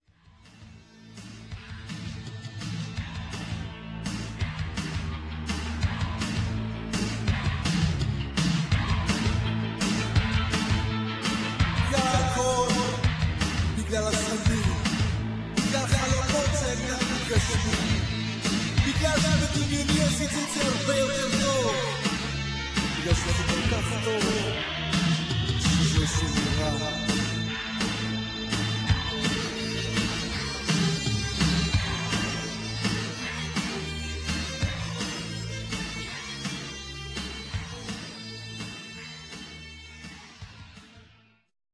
Sprechgesang